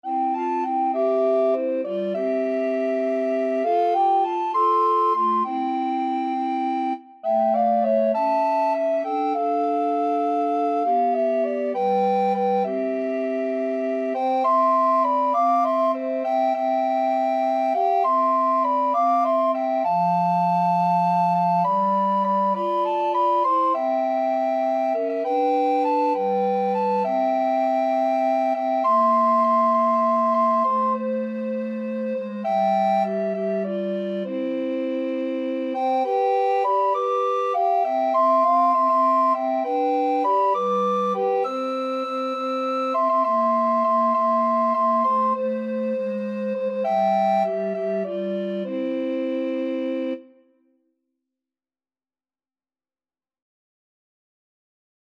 Free Sheet music for Recorder Trio
Alto RecorderTenor RecorderBass Recorder
C major (Sounding Pitch) (View more C major Music for Recorder Trio )
6/8 (View more 6/8 Music)